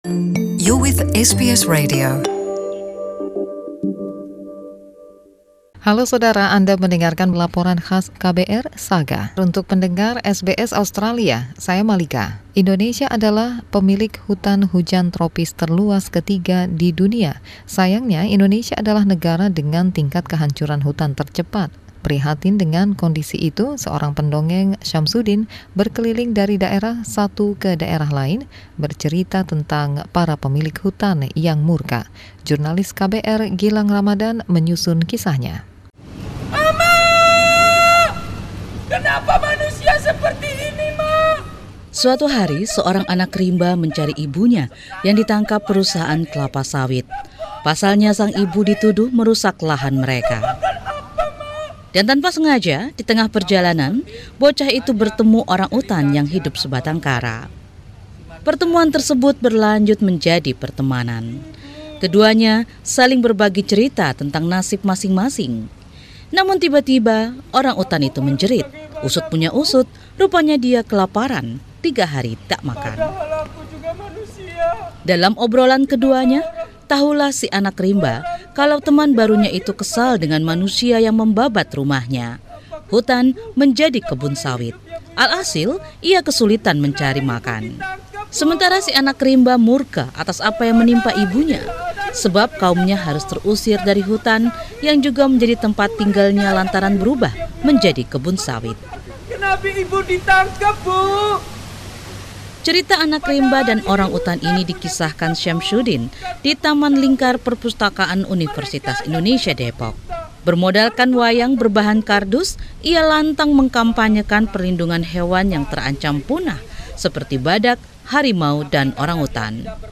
Laporan KBR 68H: Pendongeng Konservasi